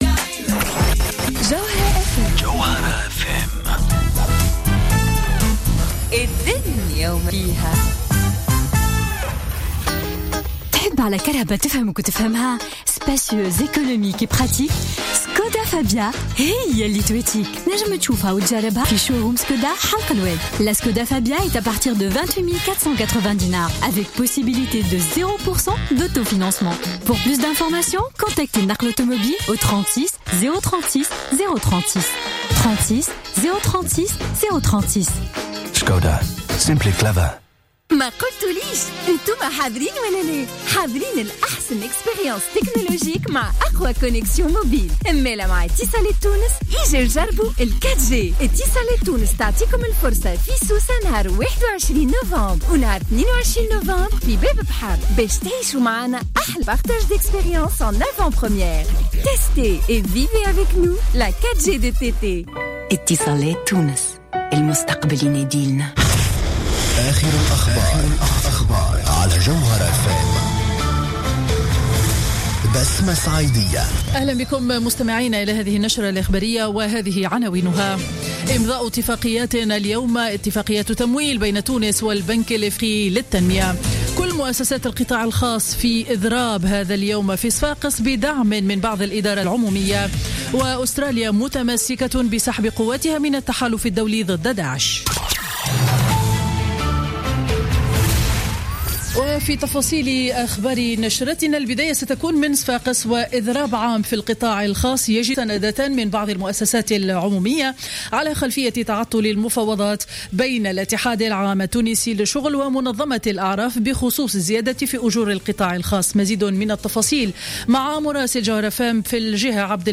نشرة أخبار السابعة صباحا ليوم الجمعة 20 نوفمبر 2015